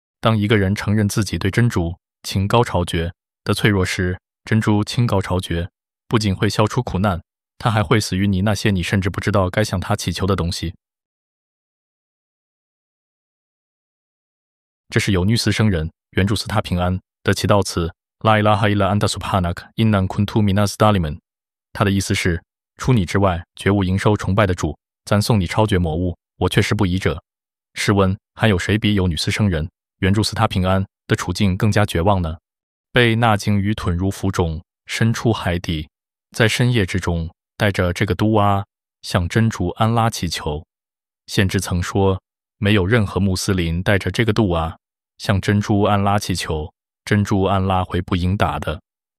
这个音频以平静而充满安慰的语气，引导人在困境中找到内心的力量与希望。它提醒人依靠信仰，保持耐心与信任。